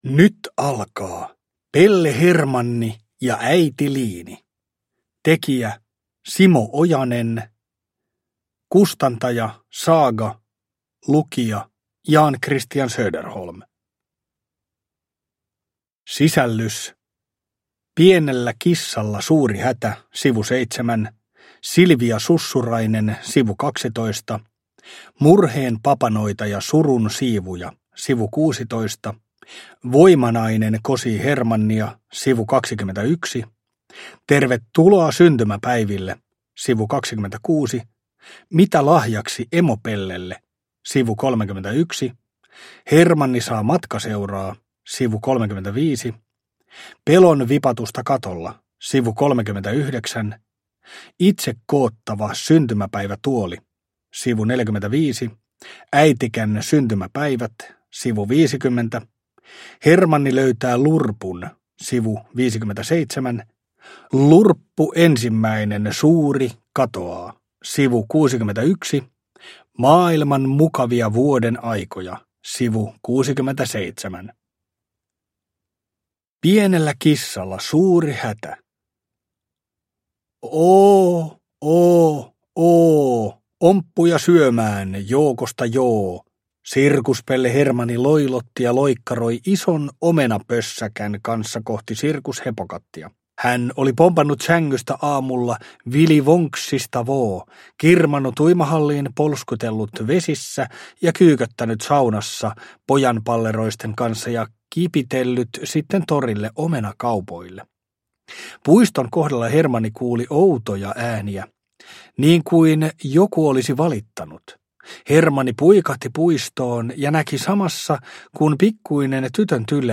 Pelle Hermanni ja äitiliini – Ljudbok – Laddas ner